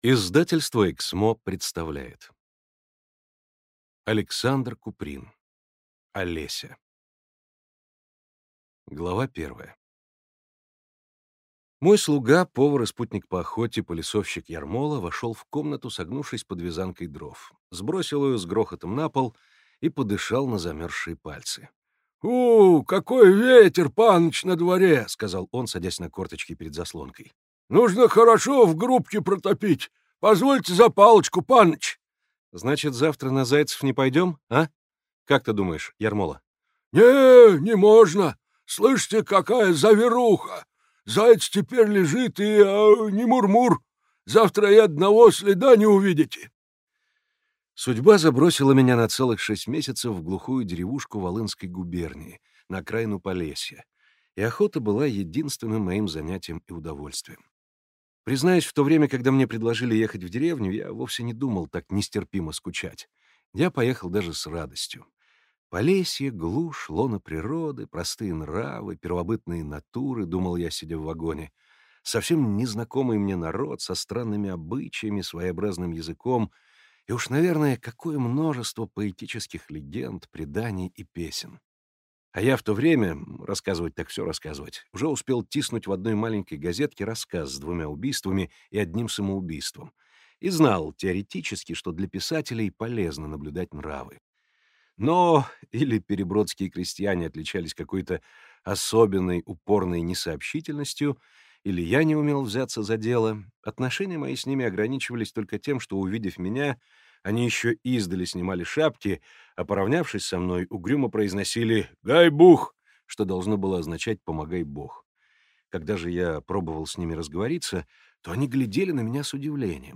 Аудиокнига Олеся | Библиотека аудиокниг